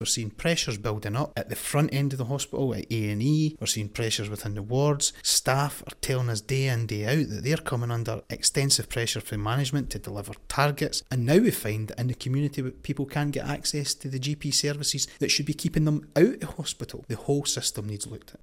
Scottish Labour's shadow health secretary, Neil Findlay warns the NHS is at 'breaking point'. It comes as Forth News reveals many local medical practices have been forced to close to new patients.